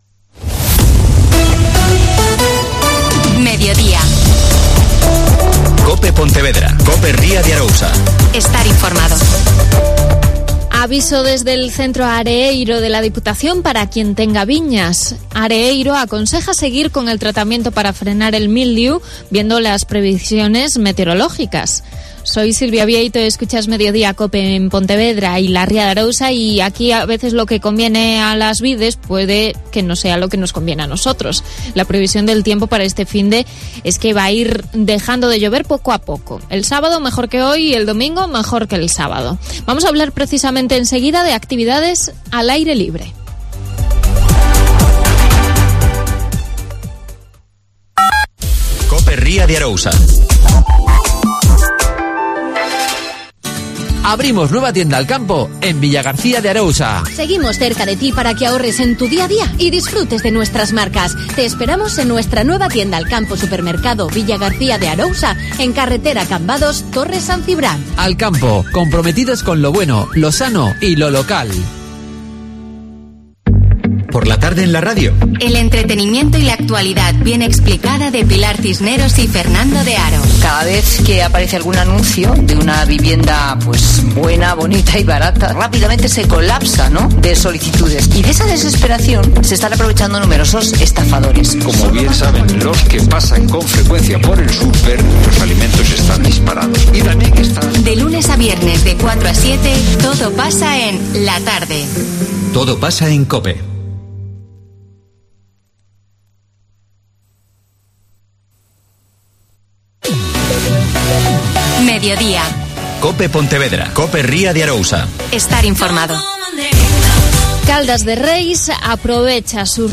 Alcalde en funciones de Caldas de Reis.